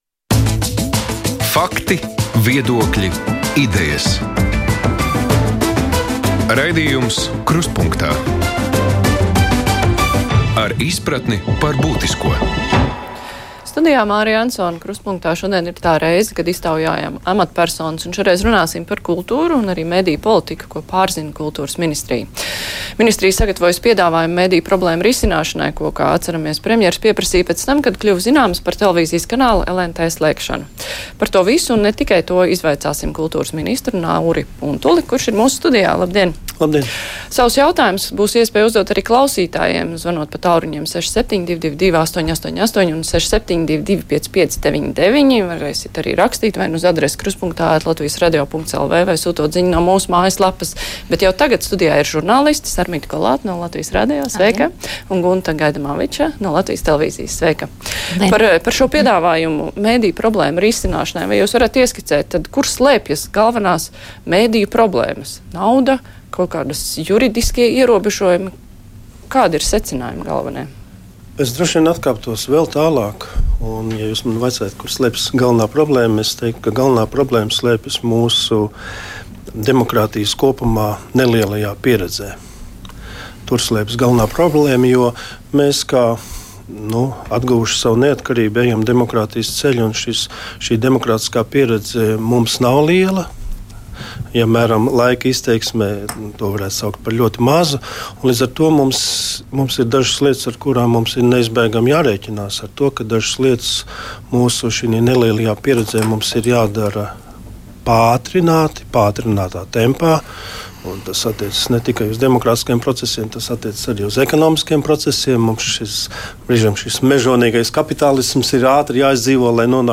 Saruna par kultūru un arī mediju politiku, kas atrodas Kultūras ministrijas paspārnē. Raidījuma viesis ir kultūras ministrs Nauris Puntulis.